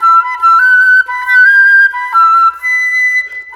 Rock-Pop 01 Flute 03.wav